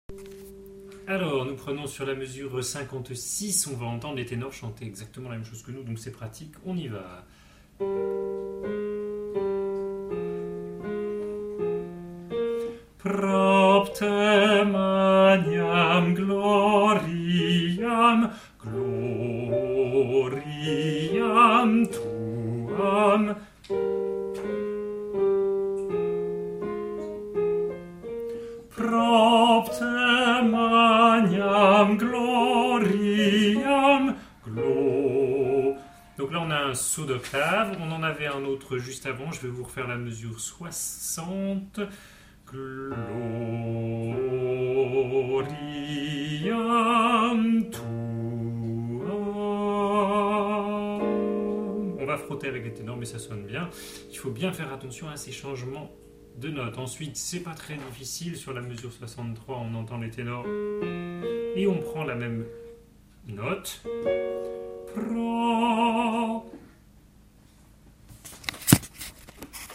Les fichiers mp3 sont deux fois plus gros que les fichiers wma, et nécessairement de moins bonne qualité sonore.